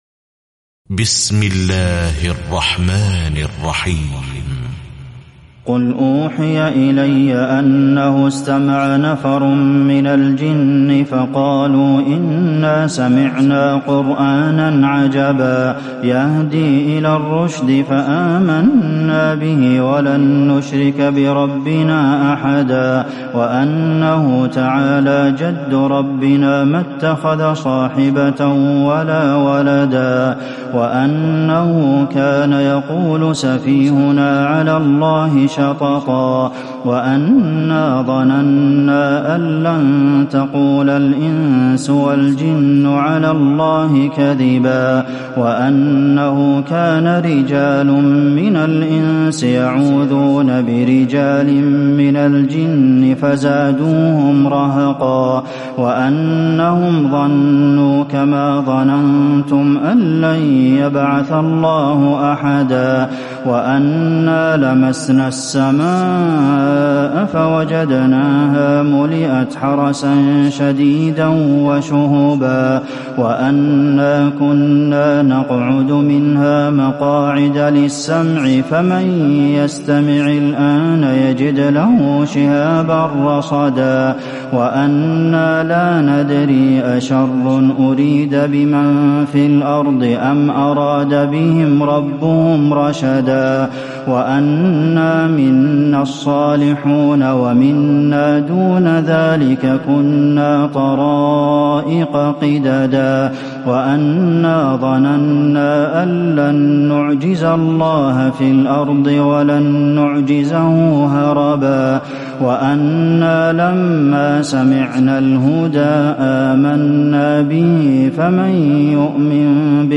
تراويح ليلة 28 رمضان 1438هـ من سورة الجن الى المرسلات Taraweeh 28 st night Ramadan 1438H from Surah Al-Jinn to Al-Mursalaat > تراويح الحرم النبوي عام 1438 🕌 > التراويح - تلاوات الحرمين